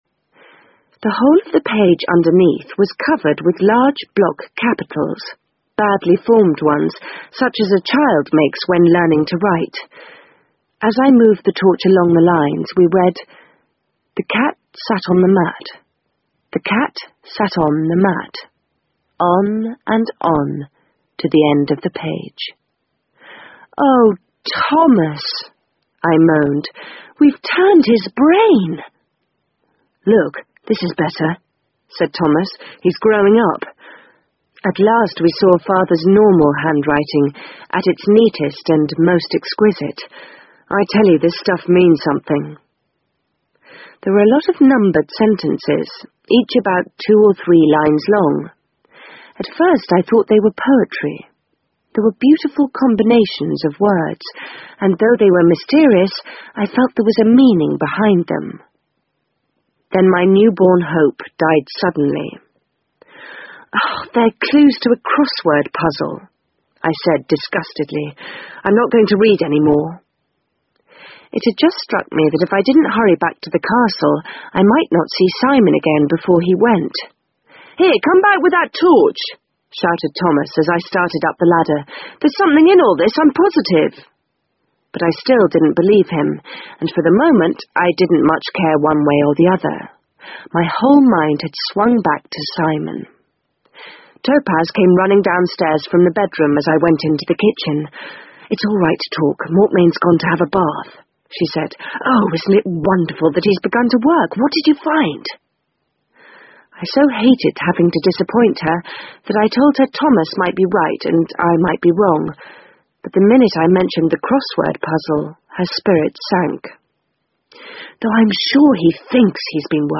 英文广播剧在线听 I Capture the Castle 56 听力文件下载—在线英语听力室